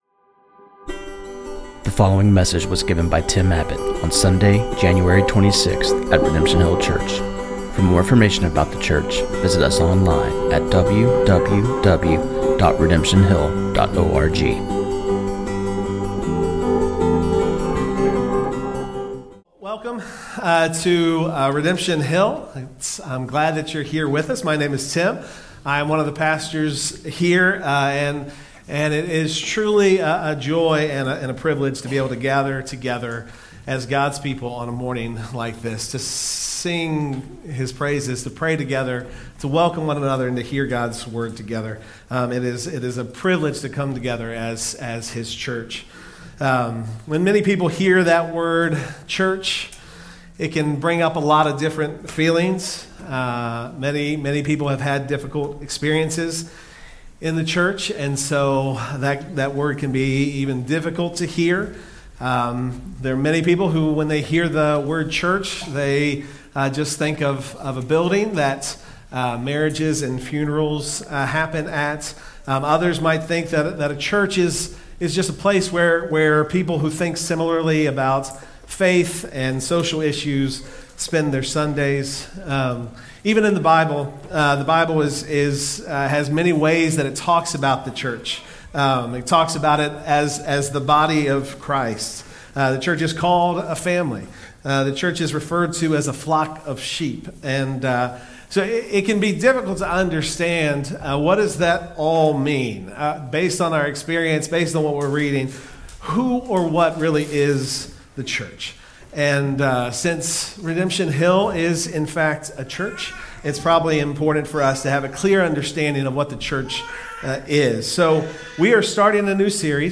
This sermon on 1 Peter 2:4-10